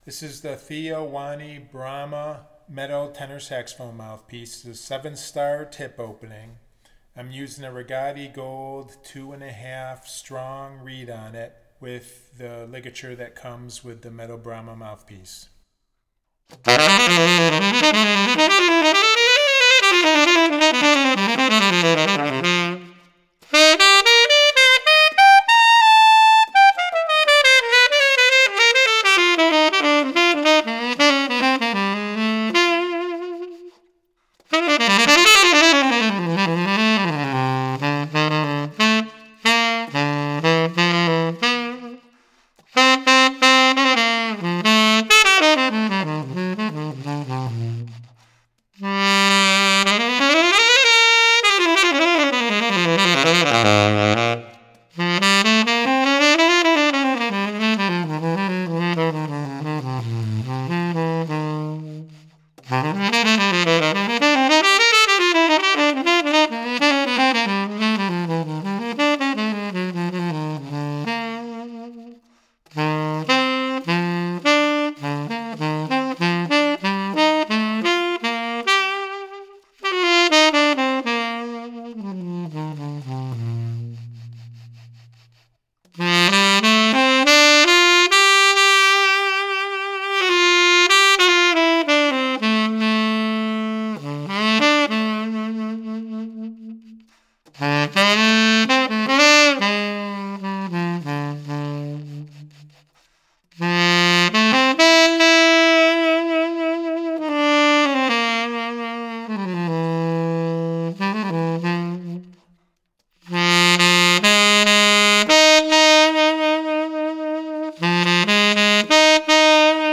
The first sound clip is recorded dry and the second sound clip is the same as the first but with a slight  reverb added.
Theo Wanne Brahma Gold Tenor Saxophone Mouthpiece – Rigotti Gold 2 1/2 Strong Reed-Reverb Added